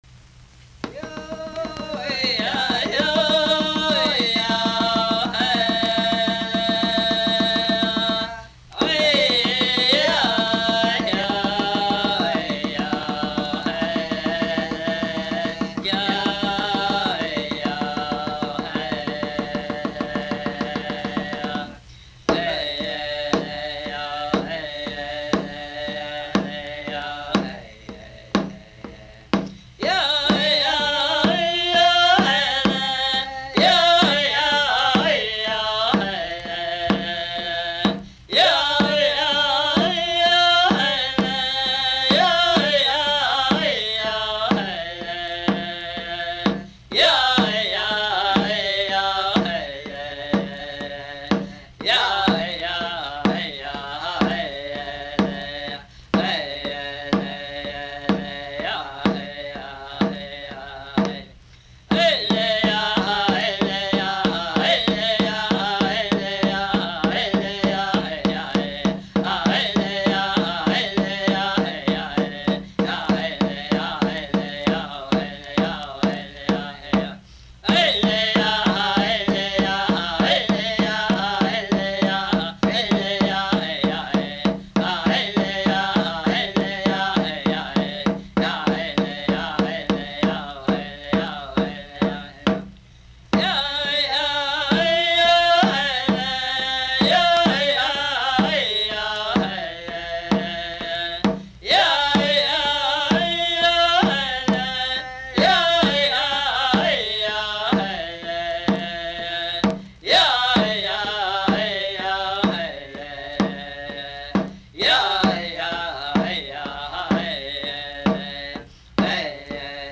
Recorded in Indian communities by Willard Rhodes, with the cooperation of the United States Office of Indian Affairs.